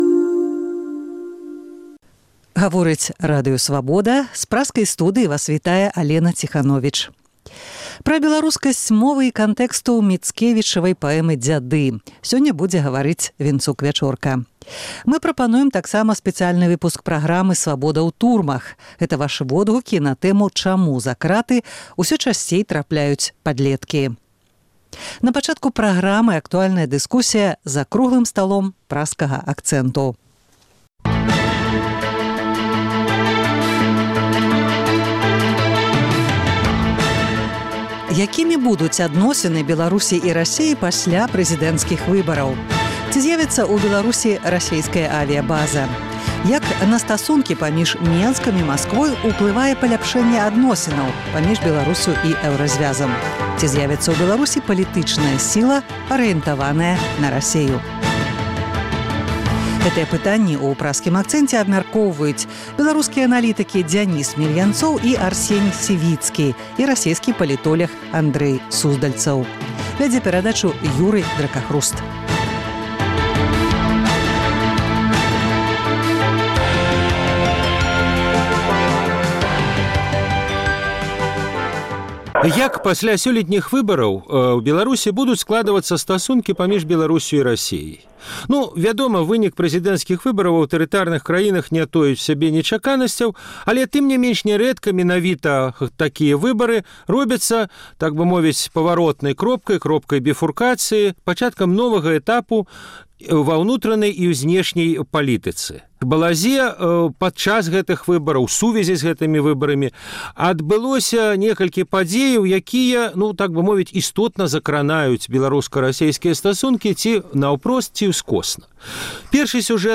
Гэтыя пытаньні ў Праскім акцэнце абмяркоўваюць беларускія аналітыкі